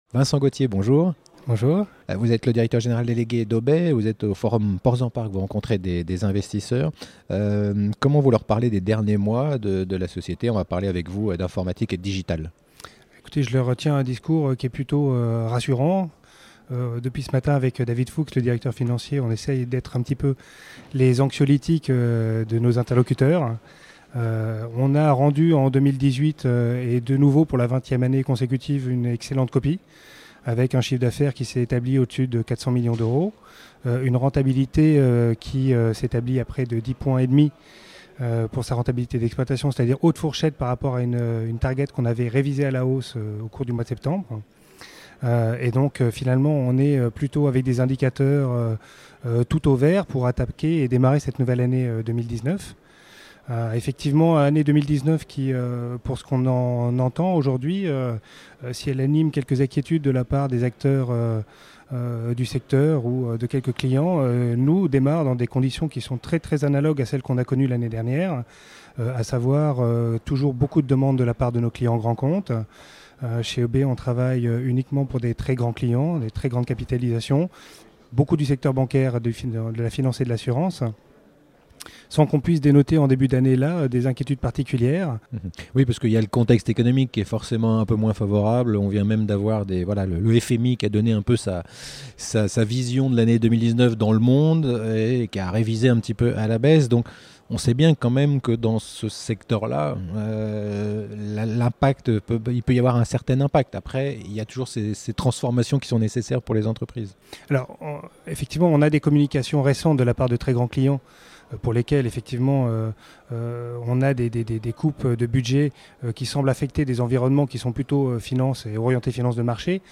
Vous vous intéressez à la société Aubay, retrouvez toutes les interviews déjà diffusées sur la Web Tv via ce lien  : Vidéos Aubay